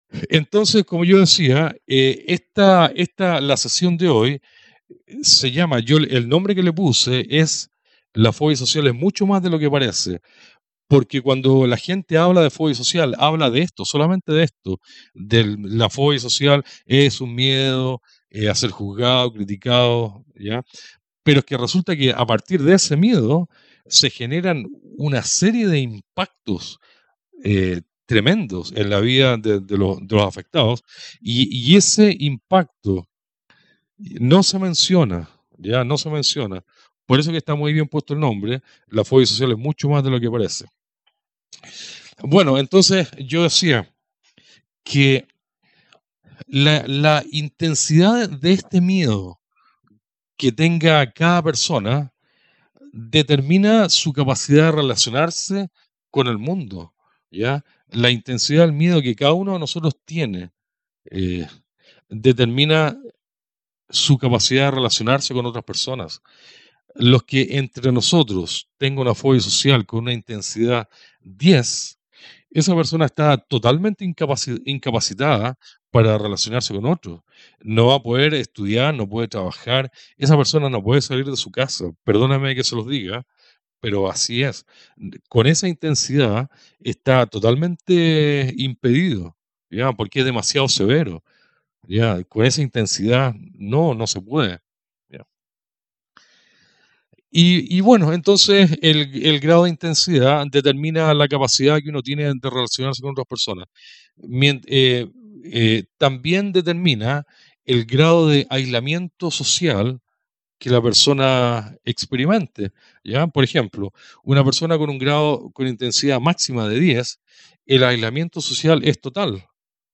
Audio de la emisión por internet de Radio Fobia Social del sábado 11 de octubre de 2014